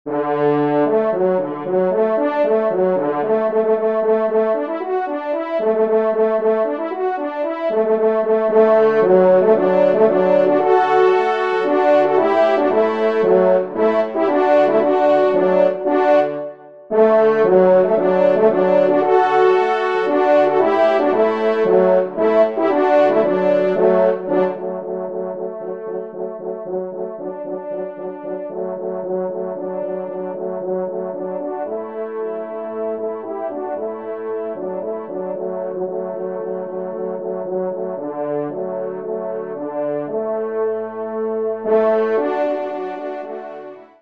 Genre :  Divertissement pour Trompes ou Cors en Ré
ENSEMBLE